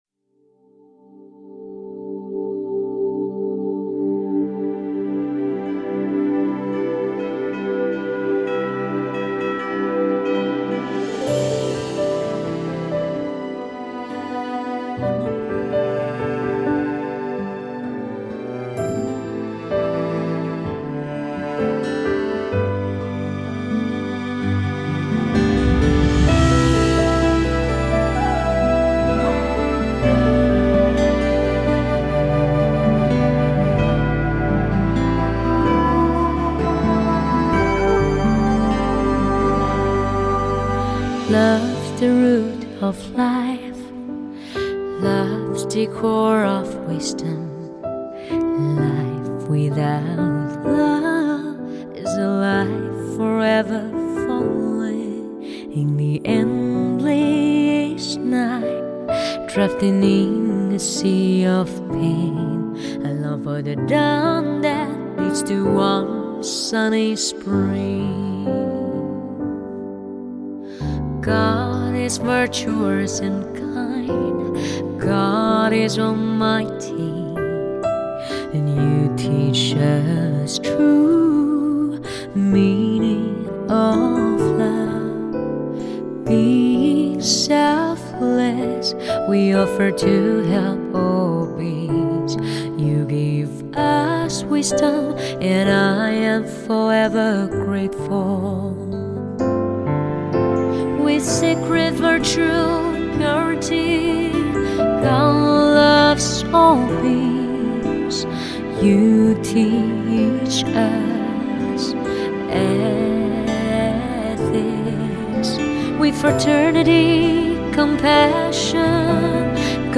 Bai Hat Than Ai The Nhan - hat tieng Anh.mp3